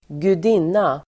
Ladda ner uttalet
gudinna.mp3